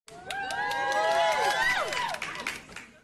Play, download and share iCarly Cheers original sound button!!!!
icarly-cheers.mp3